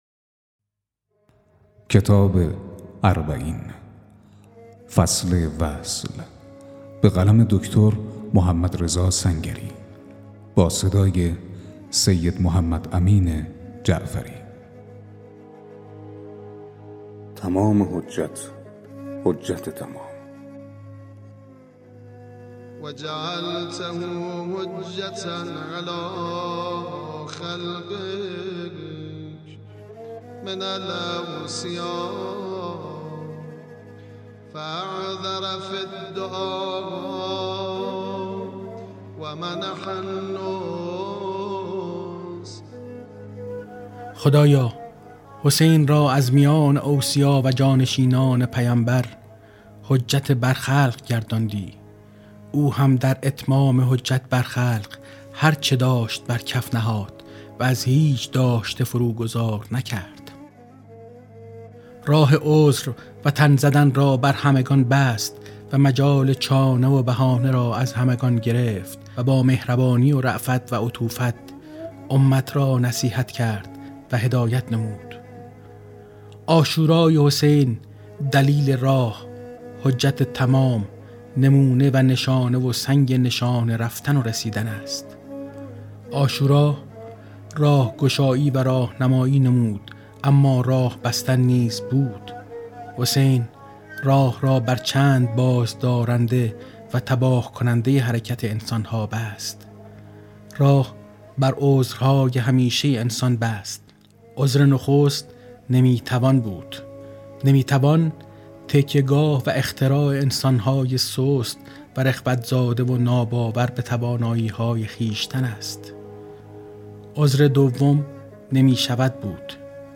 🔻ضبط و آماده‌سازی: استودیو همراز